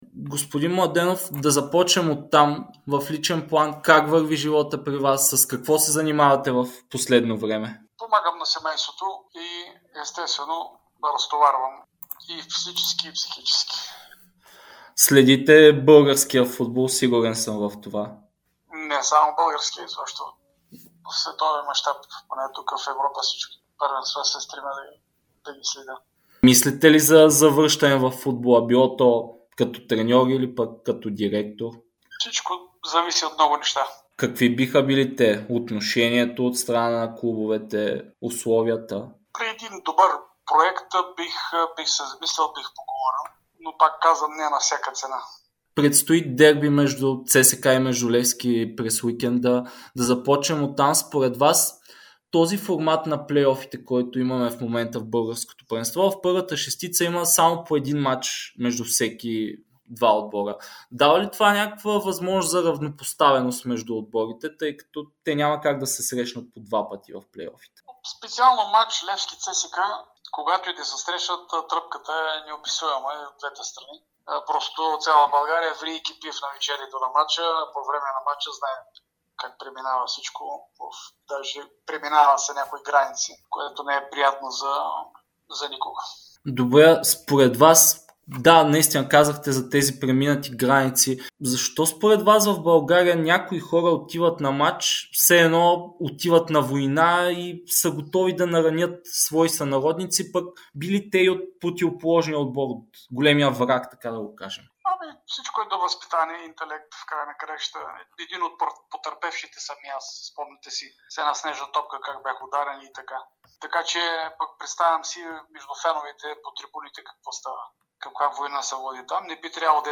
Само 2 дни преди последното дерби за сезон 2023/2024 г. Стойчо Младенов даде ексклузивно интервю пред Дарик радио и dsport, в което коментира темите около „червени“ и „сини“, както и българския футбол като цяло.